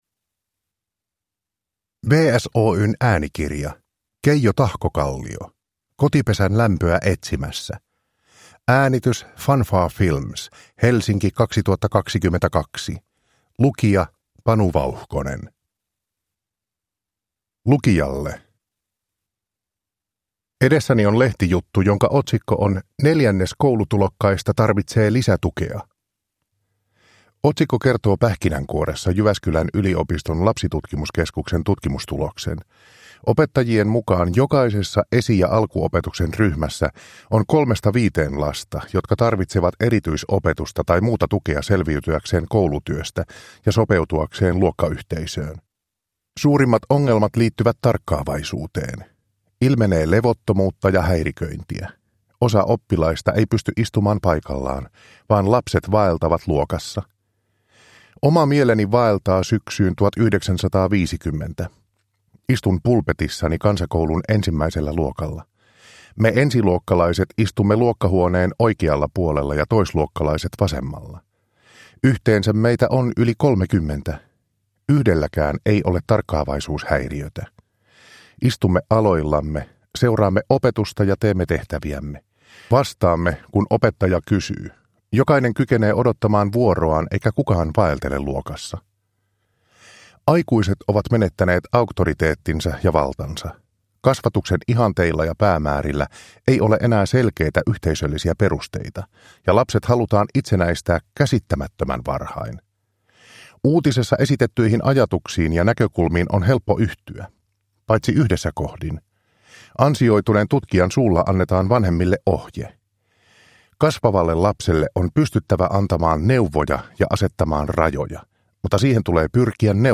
Kotipesän lämpöä etsimässä. Kirja vanhemmuudesta, rakkaudesta – Ljudbok – Laddas ner